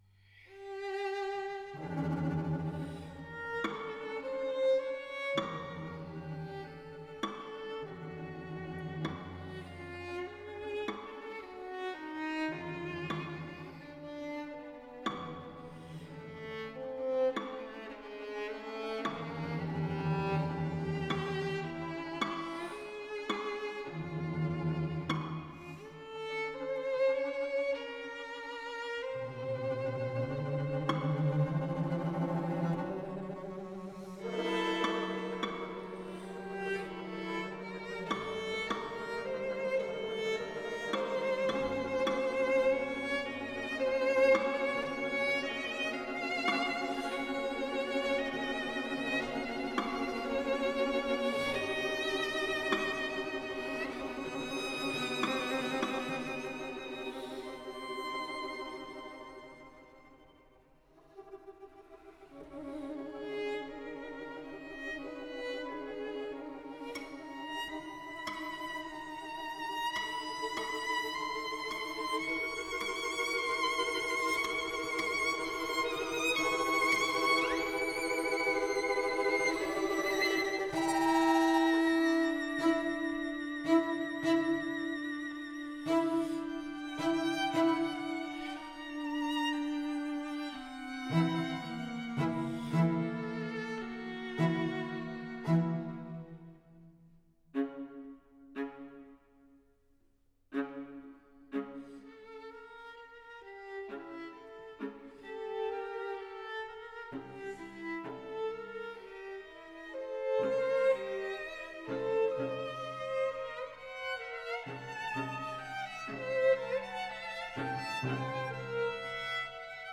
• Жанр: Классическая